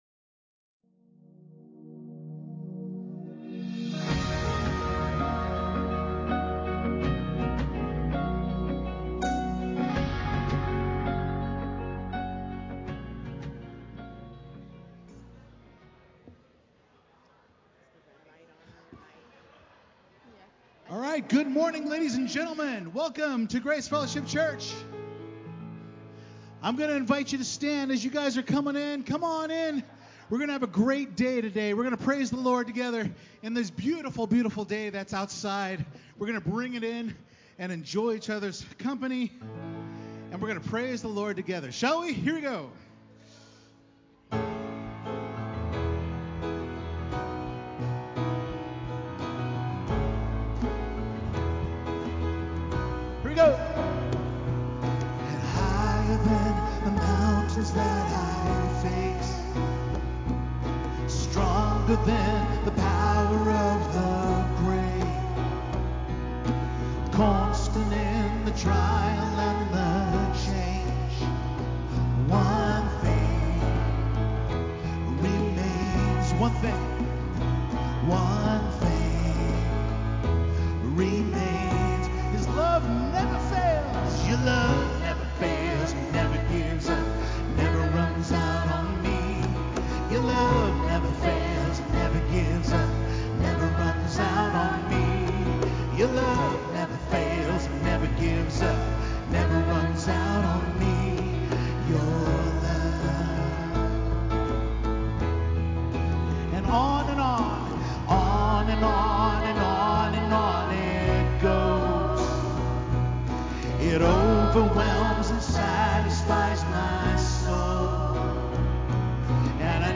The-Reputation-Of-A-Healthy-Church-Full-Service-CD.mp3